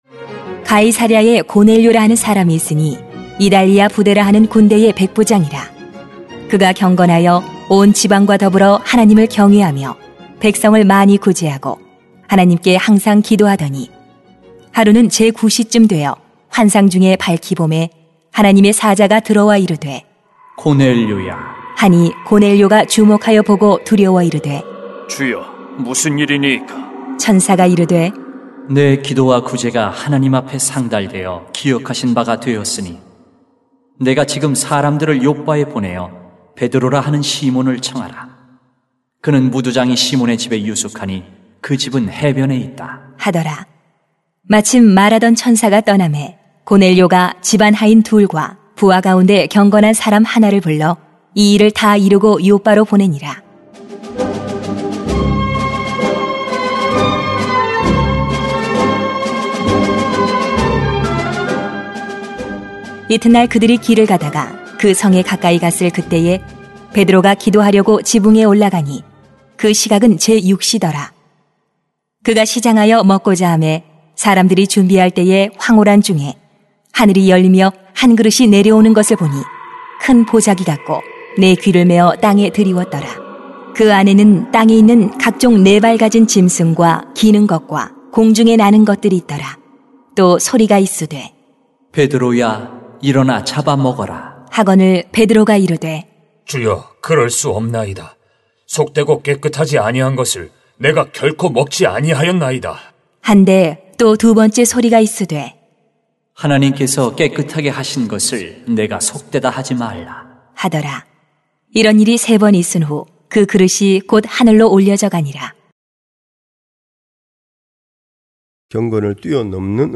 [행 10:1-16] 경건을 뛰어넘는 은혜가 필요합니다 > 새벽기도회 | 전주제자교회